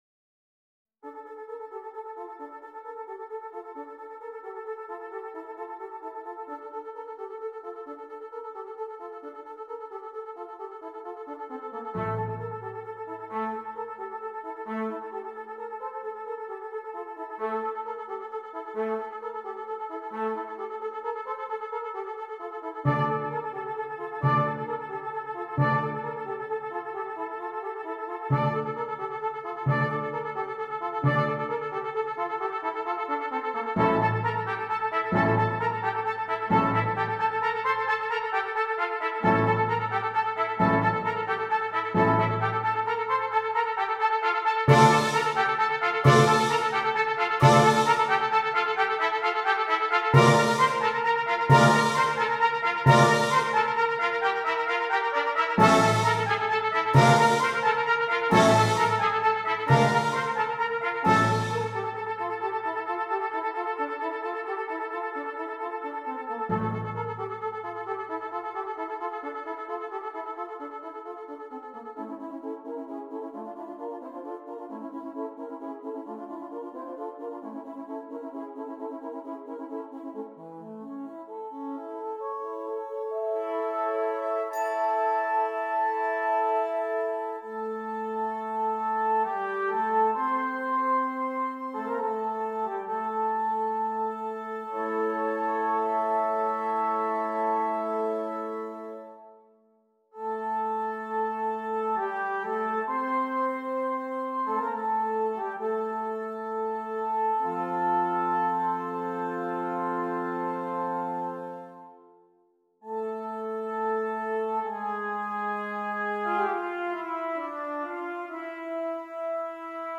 7 Trumpets and Percussion